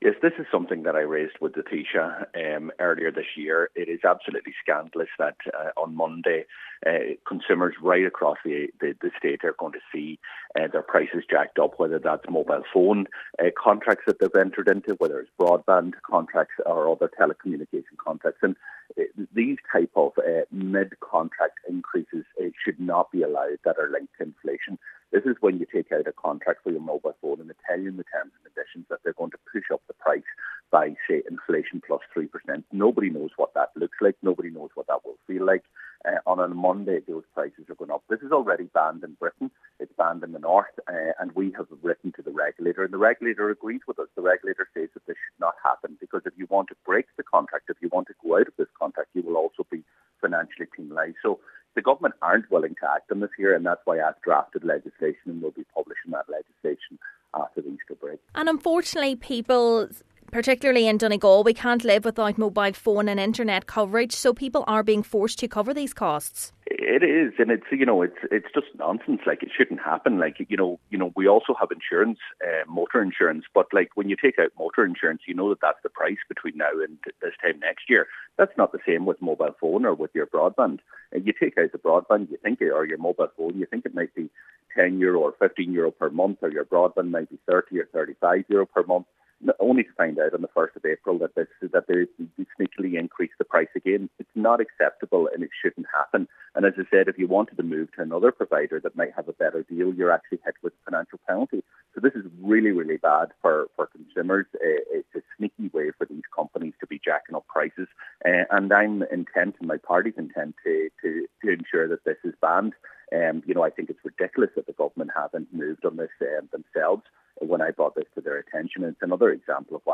Deputy Doherty says the Government needs to follow suit with others States which have banned action: